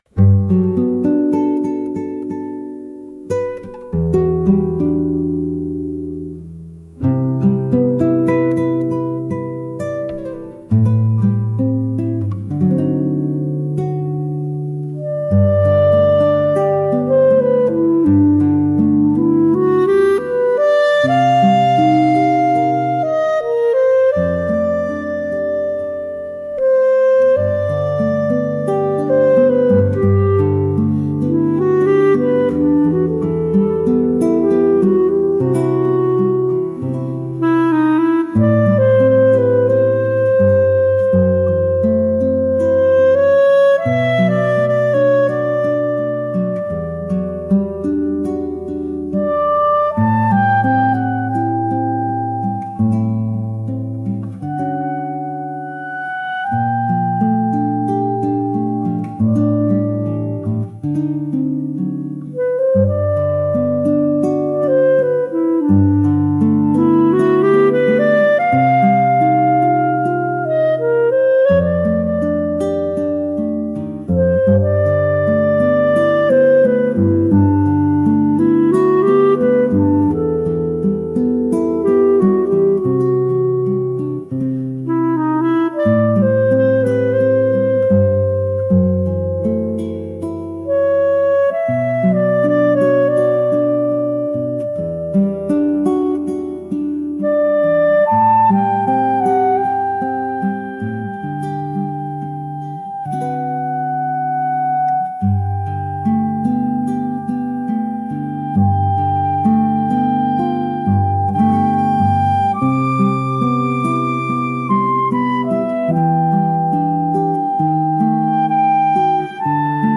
「雄大」